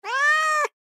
cat.mp3